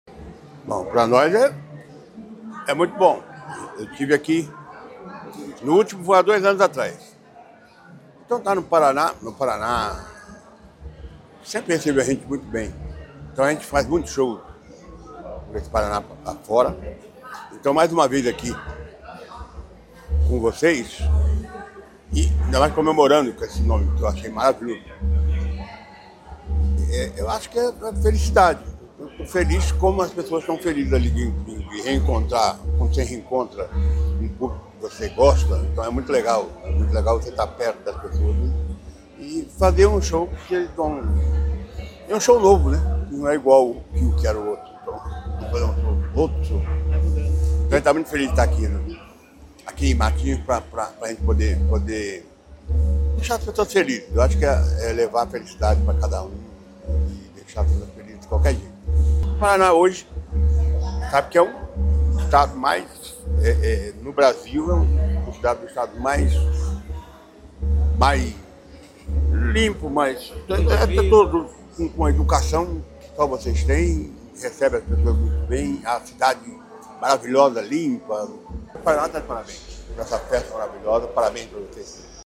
Sonora do vocalista da banda Raça Negra, Luiz Carlos, sobre o show do grupo no Verão Maior Paraná, em Matinhos, nesta sexta-feira